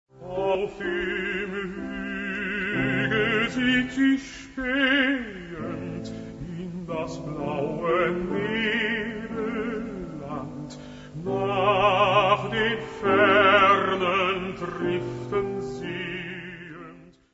۲. موسیقی کلاسیک آوازی (Classical Vocal Music)
excerpt-first-An-die-ferne-Geliebte-Dietrich-1953.mp3